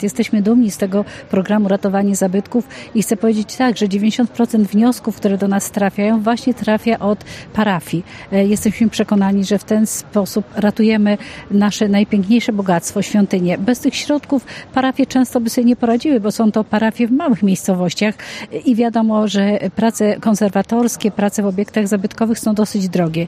O beneficjentach programu mówi Elżbieta Lanc, członkini zarządu województwa mazowieckiego: